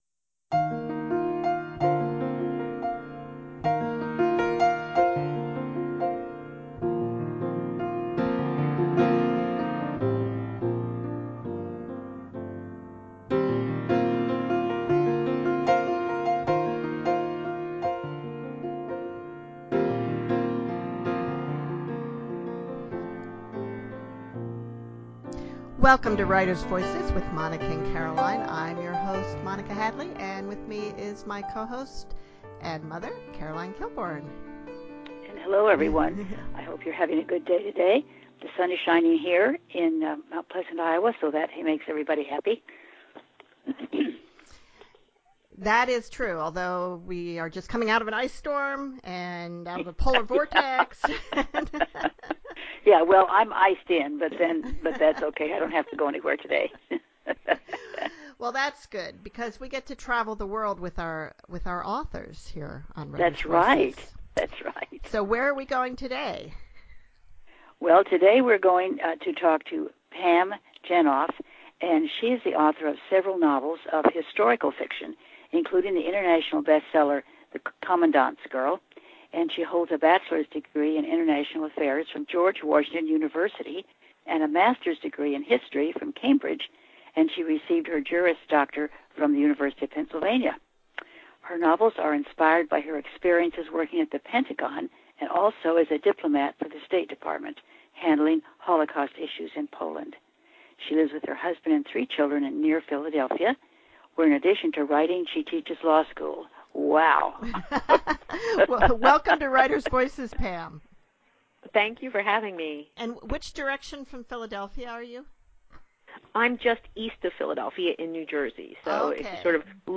Historical fiction novelist Pam Jenoff talks with Writers Voices about her fascination with WW2